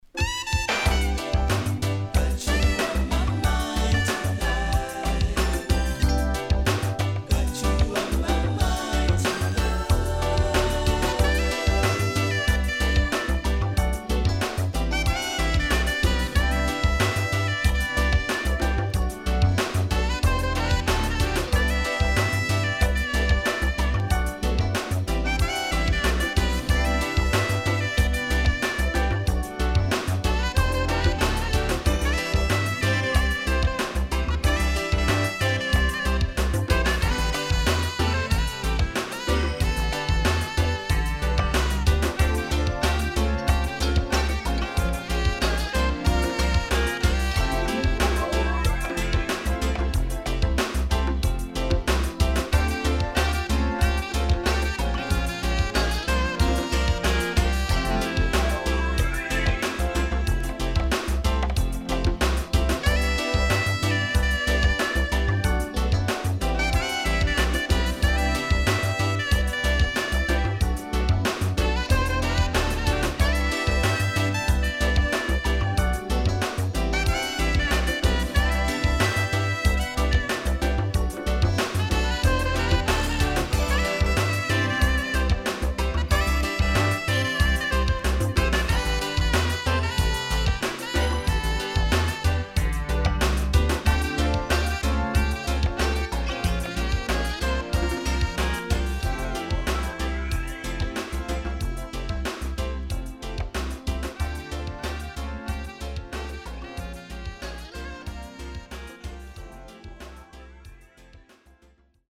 【12inch】
SIDE A:少しチリノイズ、プチノイズ入ります。